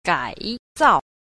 2. 改造 – gǎizào – cải tạo